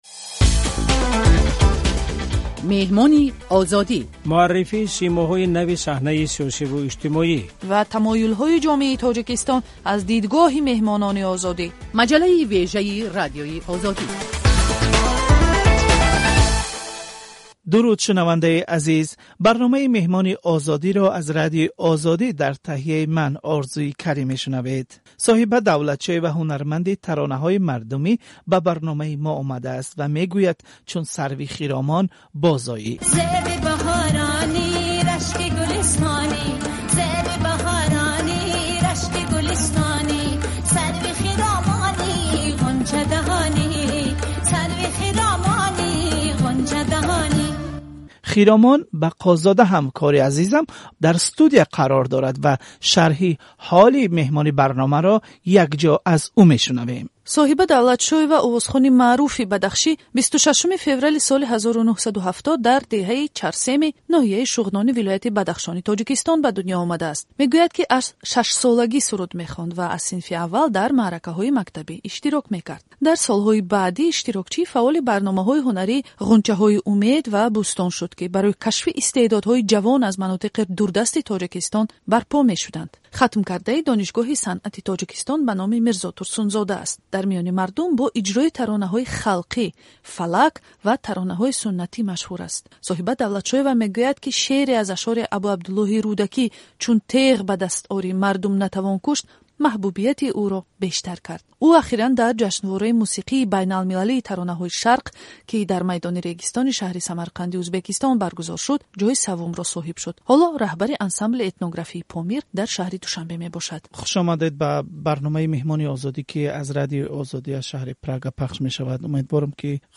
Соҳиба Давлатшоева, овозхони тоҷик, ба суолҳо дар бораи маддову рабоби бадахшонӣ ва вазъи мусиқиву овозхонӣ дар Тоҷикистон нақл кард.